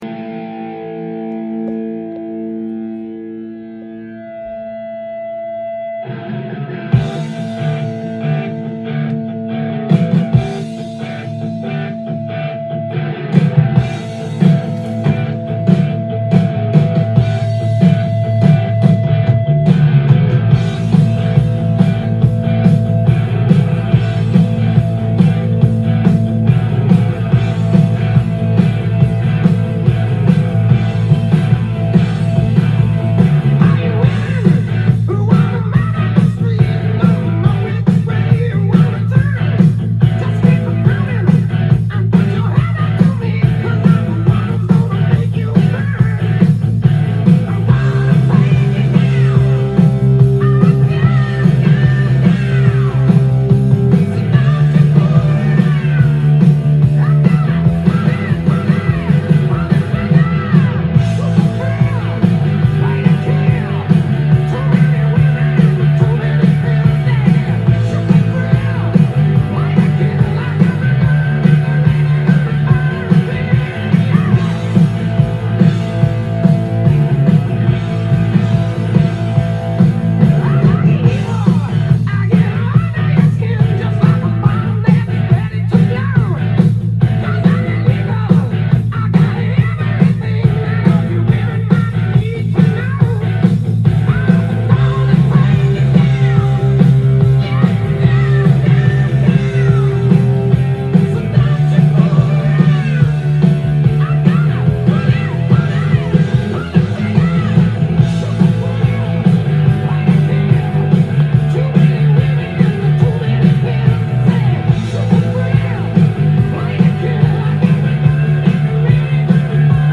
ジャンル：HARD-ROCK
店頭で録音した音源の為、多少の外部音や音質の悪さはございますが、サンプルとしてご視聴ください。
音が稀にチリ・プツ出る程度